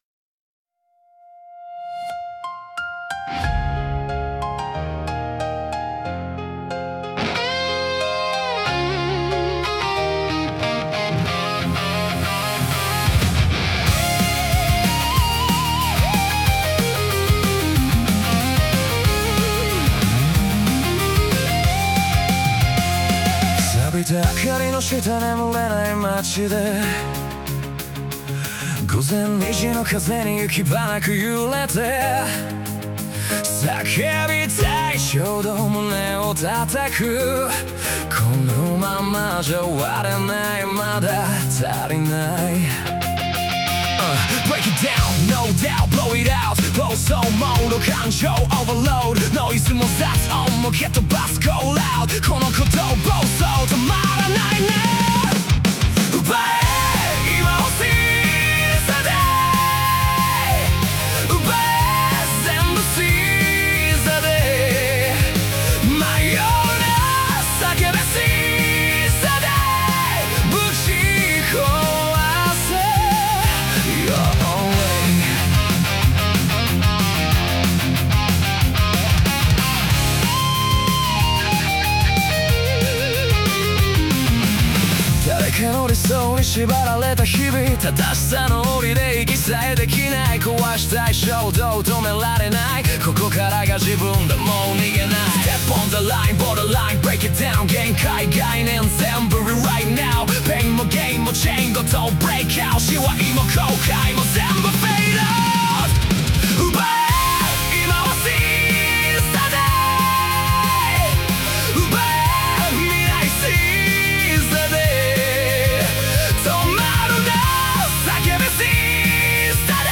男性ボーカル
イメージ：オルタナティブロック,邦ロック,J-ROCK,男性ボーカル,ラップミックス,メランコリック